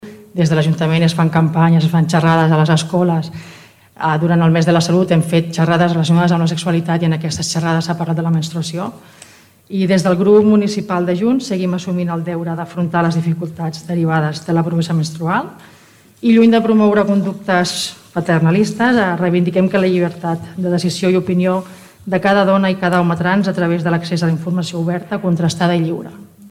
Junts per Tordera, també a l’equip de govern, votava favorablement a facilitar l’accés de la higiene femenina, d’homes i també dones trans. La regidora Nàdia Cantero, confirma que cal seguir treballant-hi: